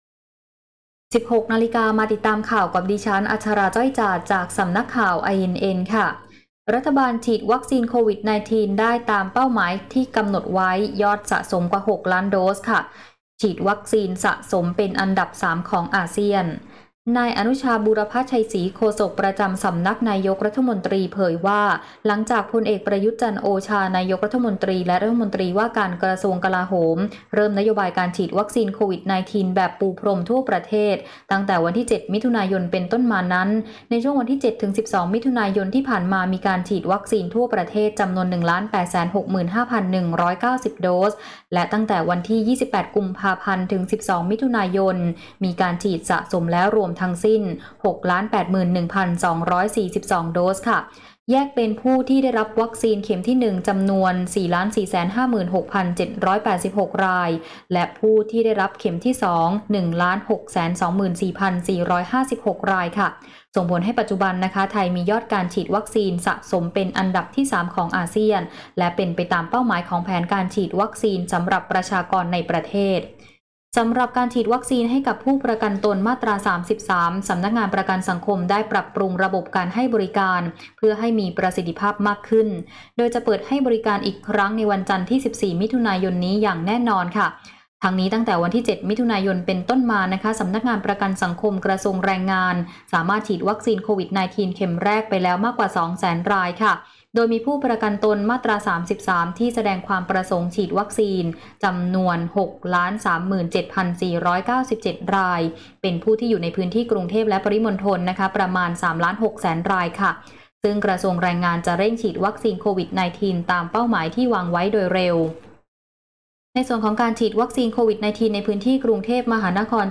ข่าวต้นชั่วโมง 16.00 น.